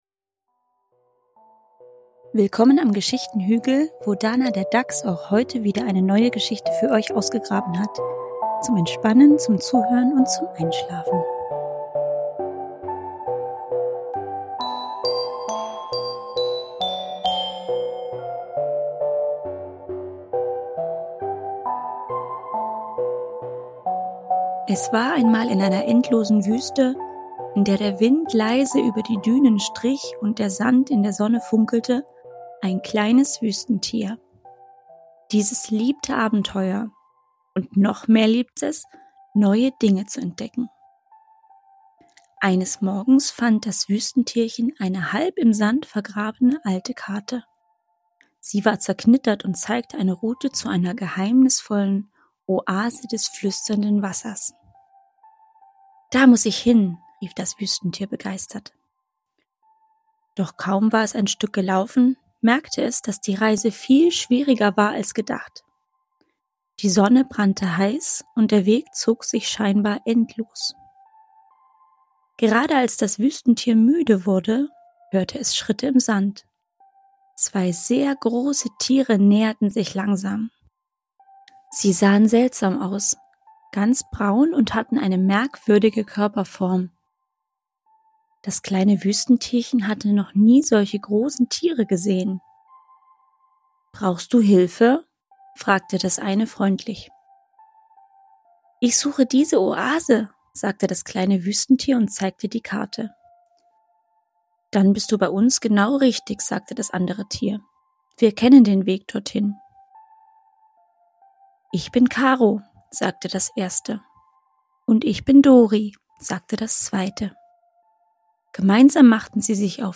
Geschichten für Kinder vom Geschichtenhügl
Ruhige Geschichten für Kinder – zum Entspannen, Zuhören und Einschlafen.
erzählten Geschichte – ohne Werbeunterbrechung und ohne Hektik.